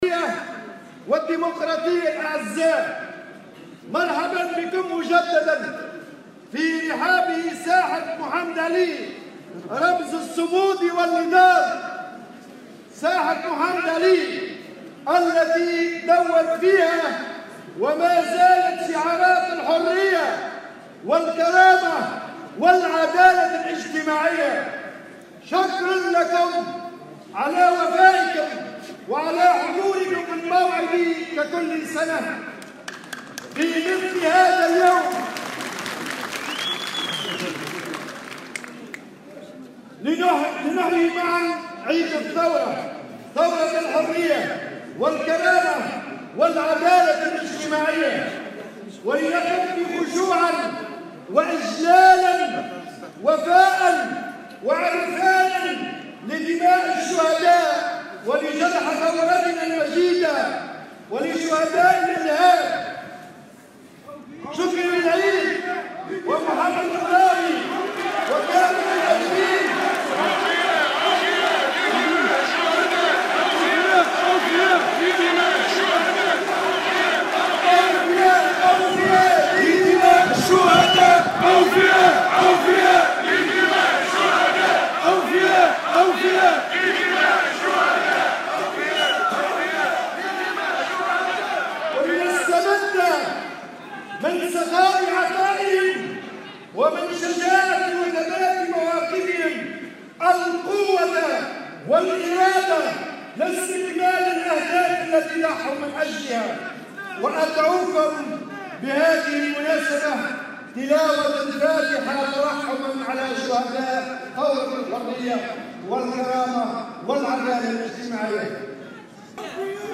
قال الأمين العام الاتحاد العام التونسي للشغل نور الدين الطبوبي إن حكومة "النقد الدولي" دفعت الاتحاد إلى اقرار الاضراب العام المقرر يوم 17 جانفي 2019، مؤكدا تمسك الاتحاد بهذا الاضراب في خطاب ألقاه خلال تجمع عمالي بمناسبة الذكرى الثامنة للثورة التونسية اليوم الاثنين 14 جانفي 2019.